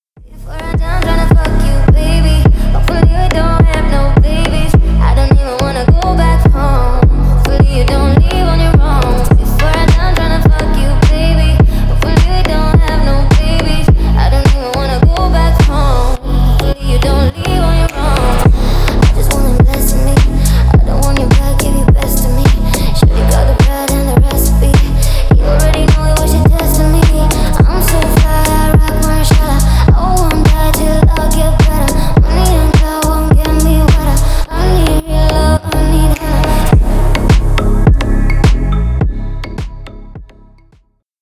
Поп Музыка
тихие